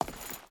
Footsteps
Stone Chain Walk 4.ogg